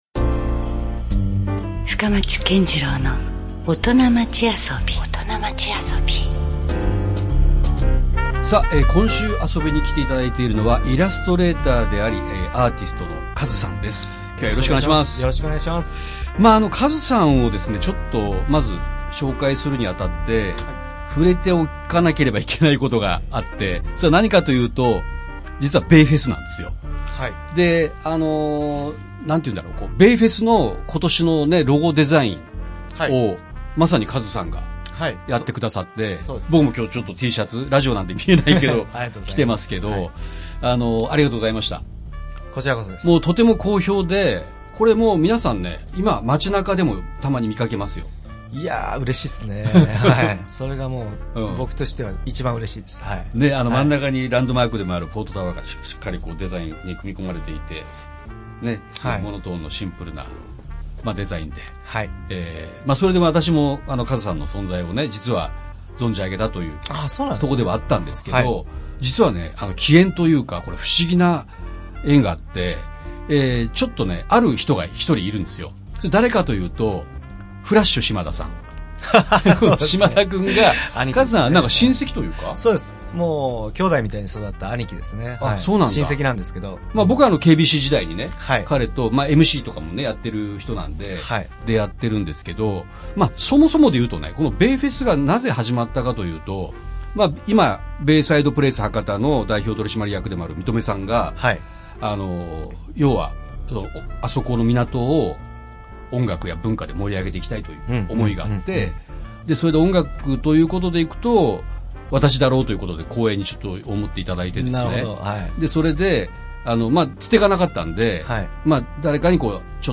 アソビに来て頂いた全てのオトナの皆様とのトークを、Podcastアーカイブとして保存しております。